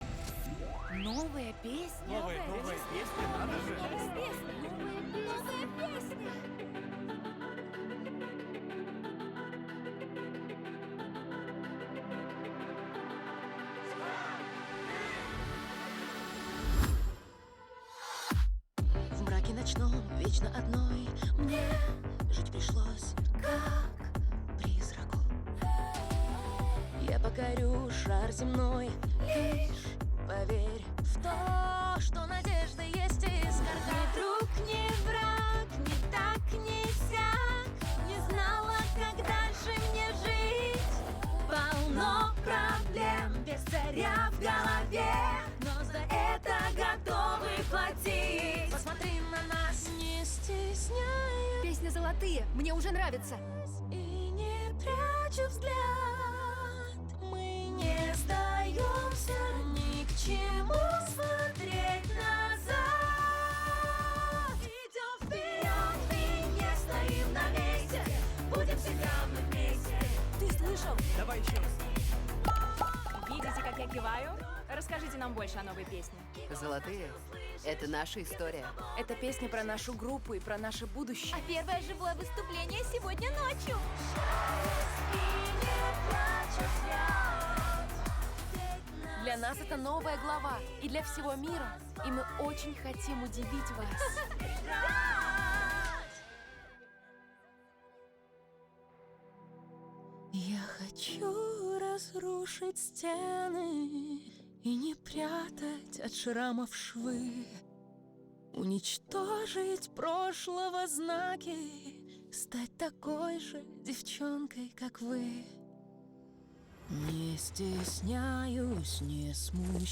Качество: 320 kbps, stereo
Кей поп кейпоп